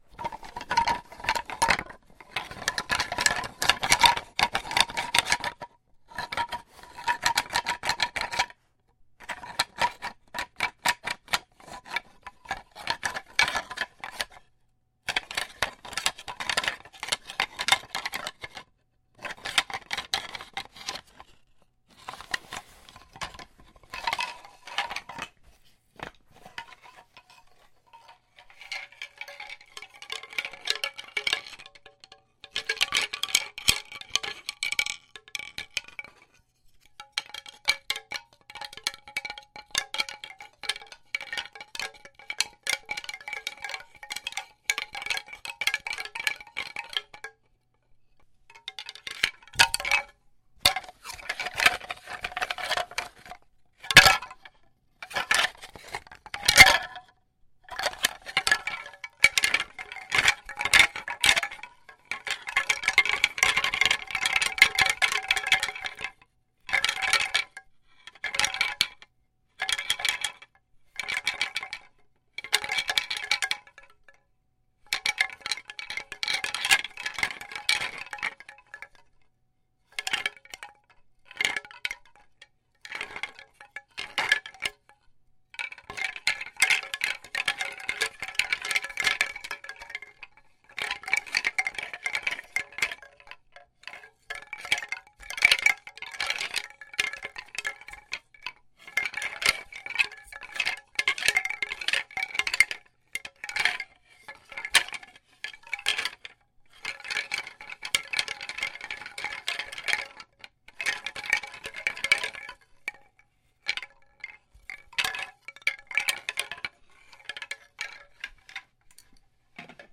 Звуки бамбука
Шум собирающихся бамбуковых палок в кучу